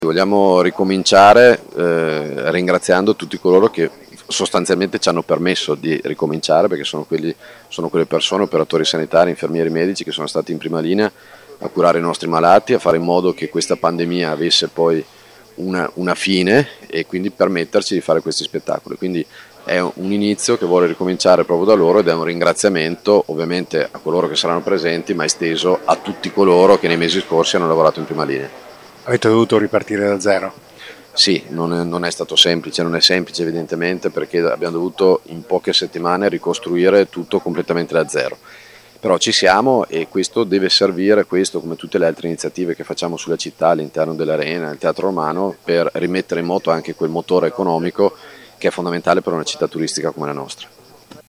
eccolo al microfono del nostro corrispondente